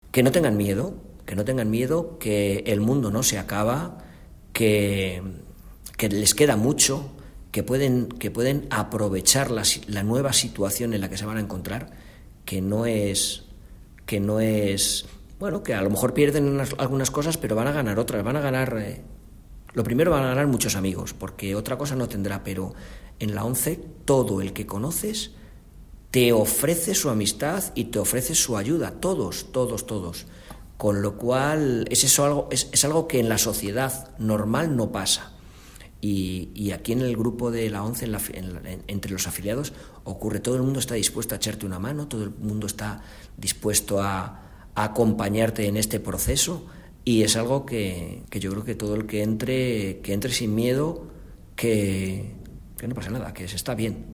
De hablar pausado y tranquilo